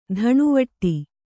♪ dhaṇuvaṭṭi